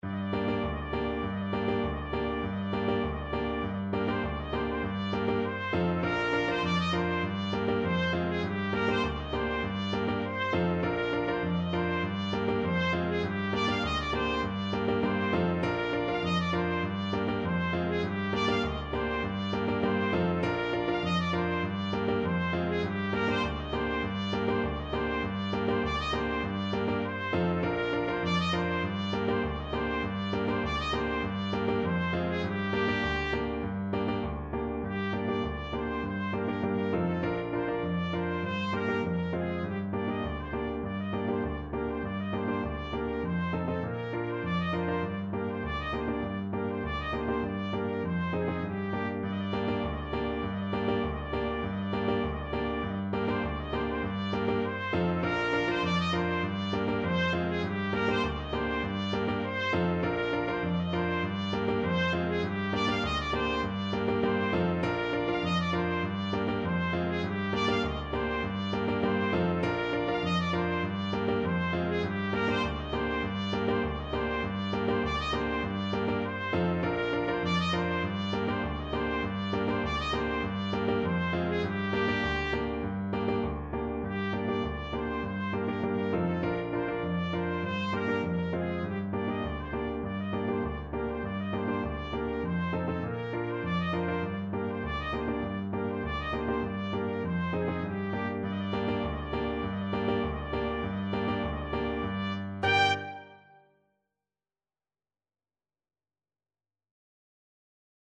World Europe Greece Ikariotikos (Greek Folk Song)
Traditional Music of unknown author.
C minor (Sounding Pitch) D minor (Trumpet in Bb) (View more C minor Music for Trumpet )
Moderato
4/4 (View more 4/4 Music)
Trumpet  (View more Intermediate Trumpet Music)